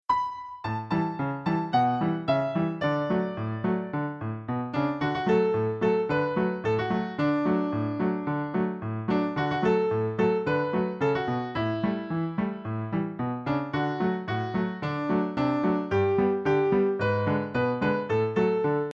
Sheet Music — Piano Solo Download
Piano Solo
Downloadable Instrumental Track